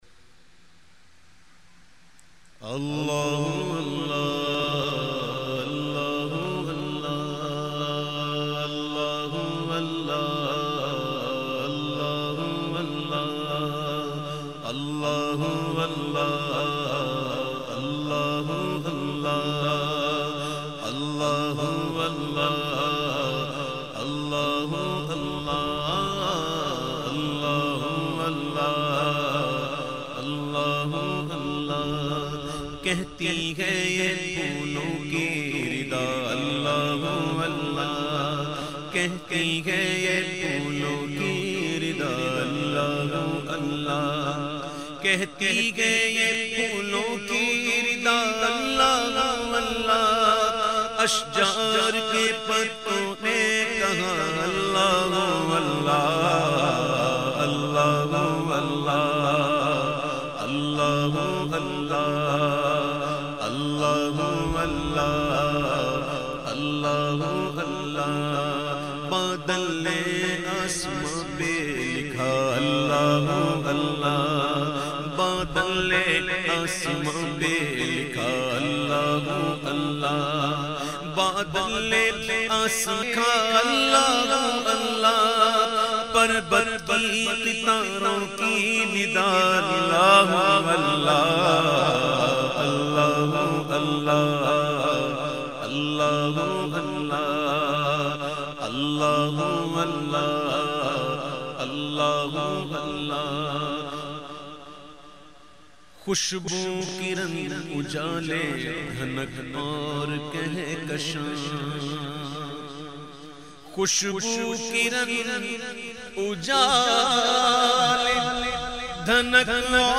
Hamd